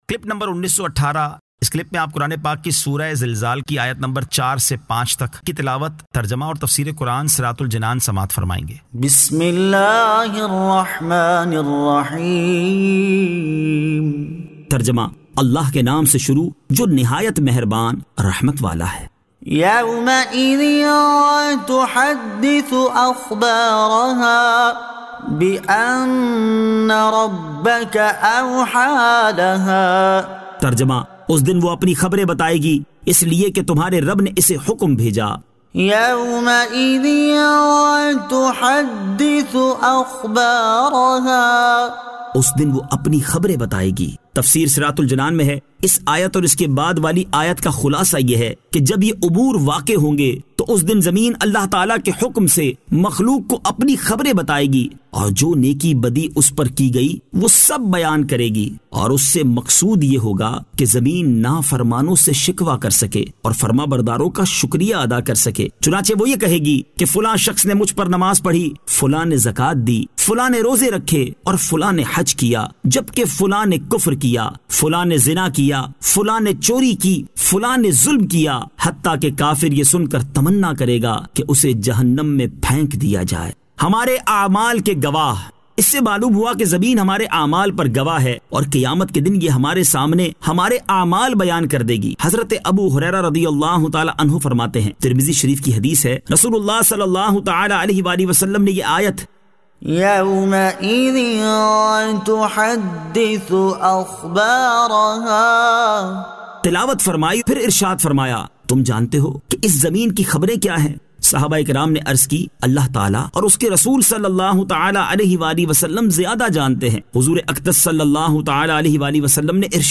Surah Al-Zilzal 04 To 05 Tilawat , Tarjama , Tafseer